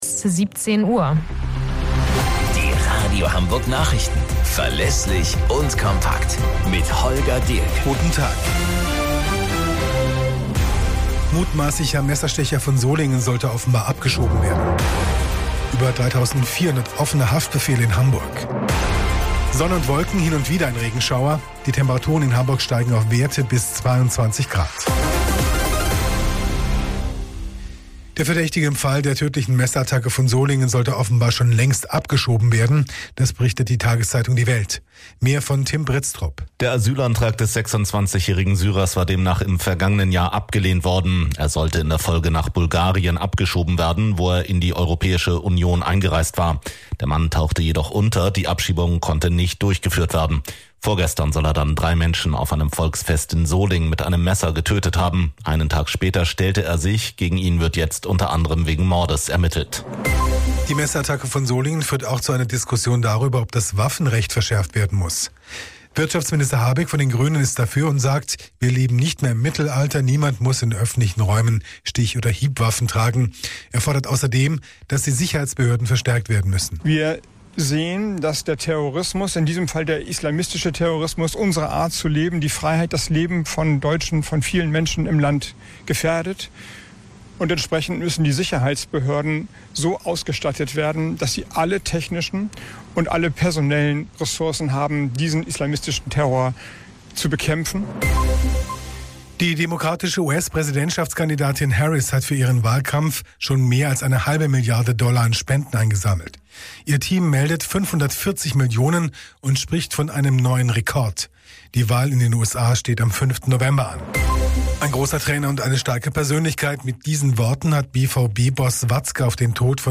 Radio Hamburg Nachrichten vom 26.08.2024 um 00 Uhr - 26.08.2024